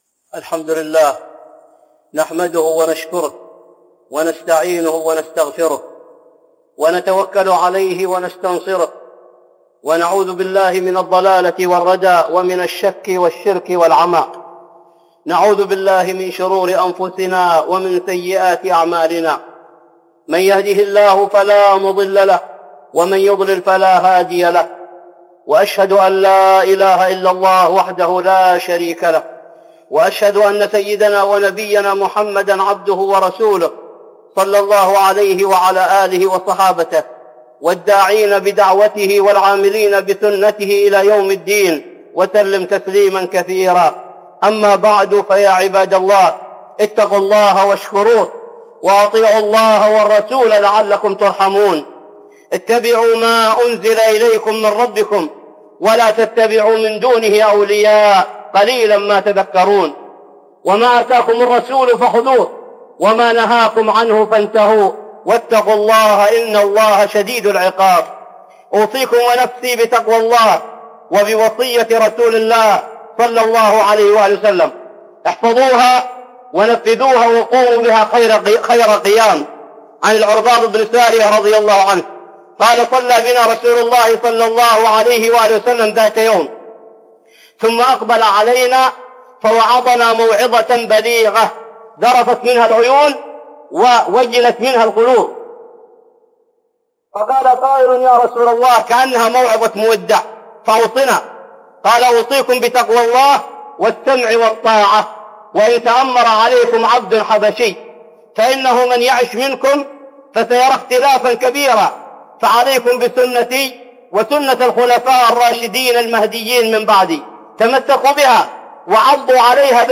(خطبة جمعة) بعنوان